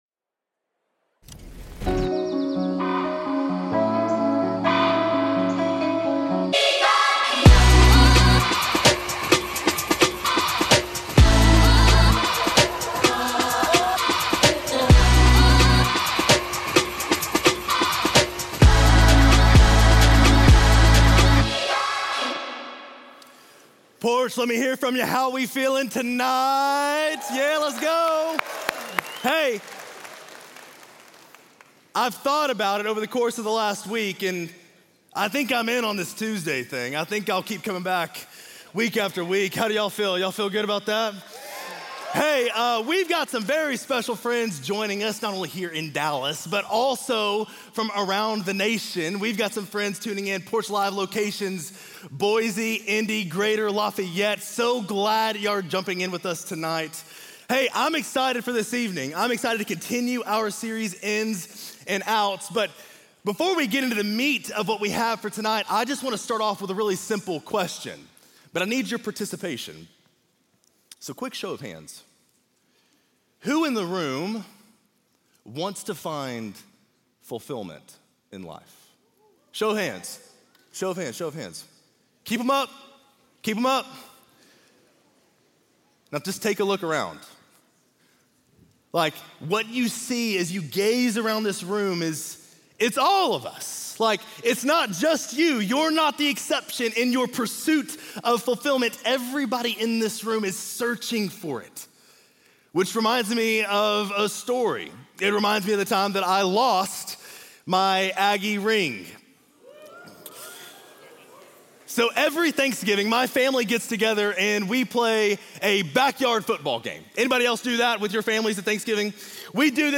Religion & Spirituality, Christianity